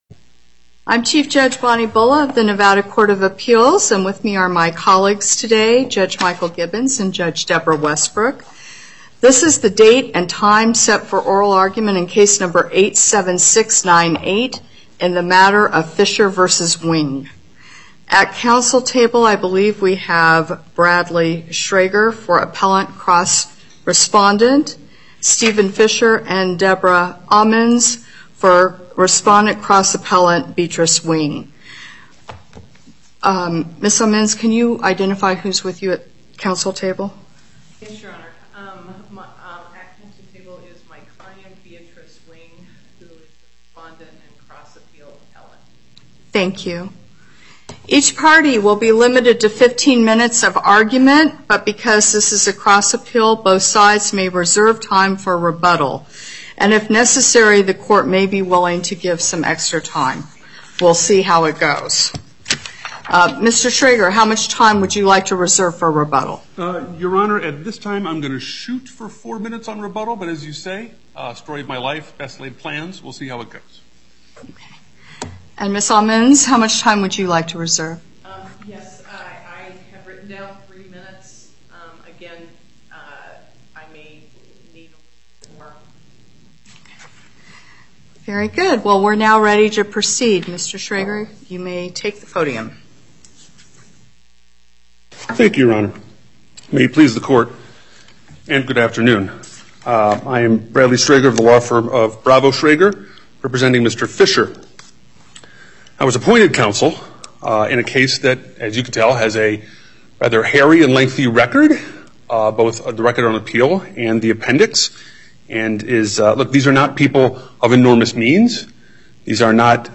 Before the Court of Appeals, Chief Judge Bulla presiding